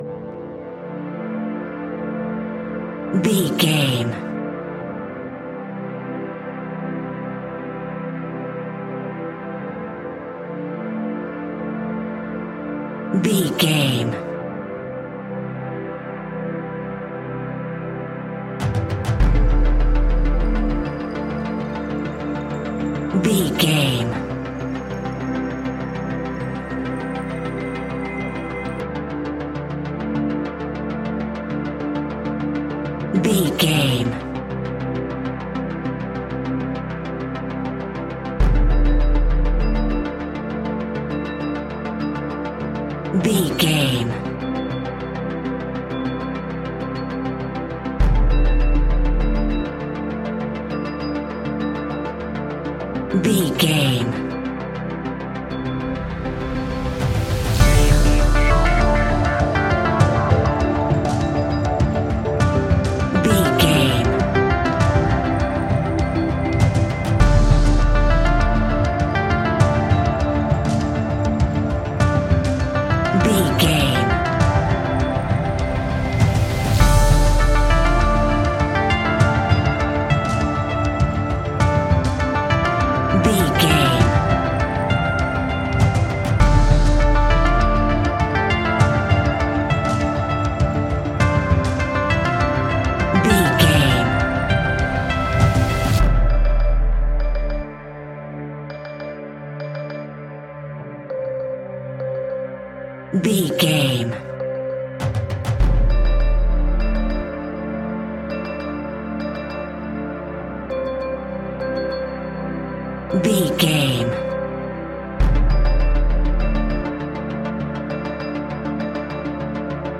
royalty free music
Aeolian/Minor
scary
ominous
dark
eerie
synthesiser
drums
percussion
instrumentals
horror music